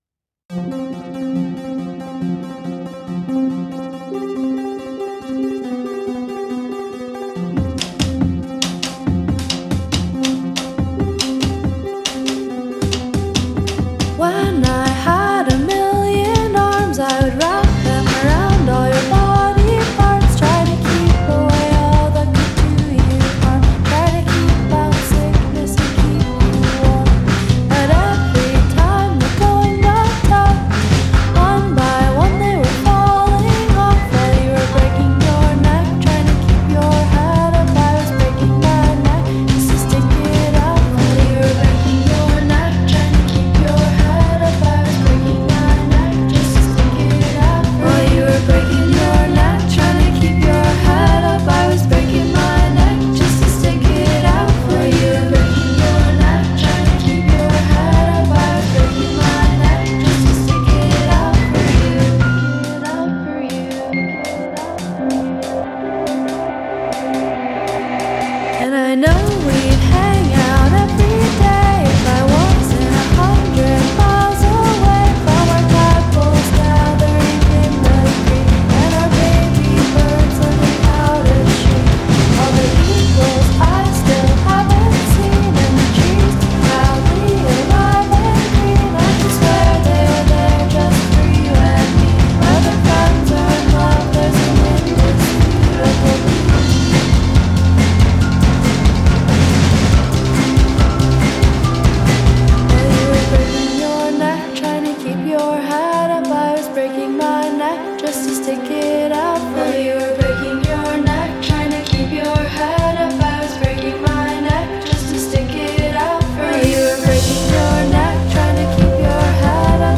Indie Indie pop Synthpop